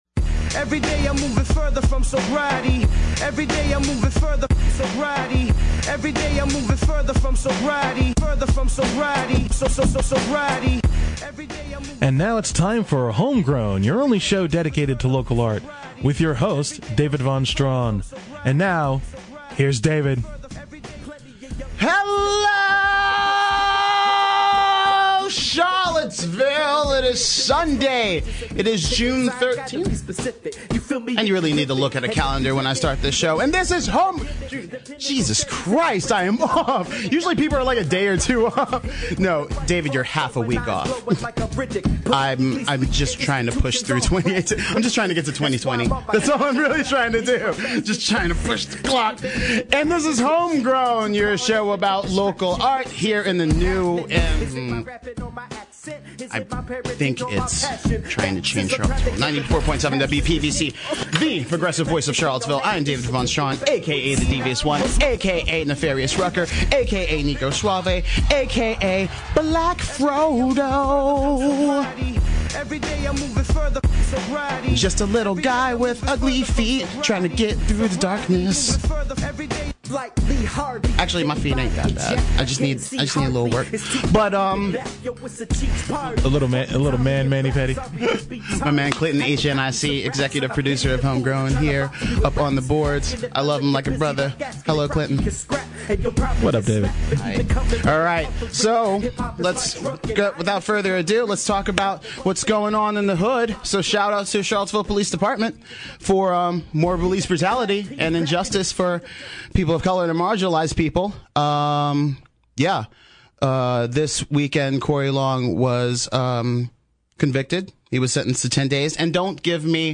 They strap on the intruments and give us a taste of the music before talking about reggae and unity before their concert in Crozet .